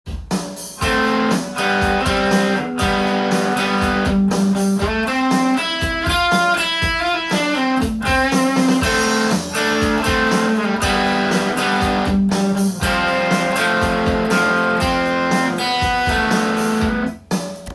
アレンジしたギターパート
コード進行は、同じでAm/G/F/Fになっていますが
パワーコードを完全に無くしました。
arreng.chord_.m4a